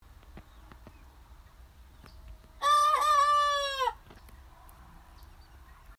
Without further delay, I now present the first recorded audio of one of Rocky’s early crowing practices:
You gotta agree, that sounds pretty cute, eh?
rocky-cockerel-crow.mp3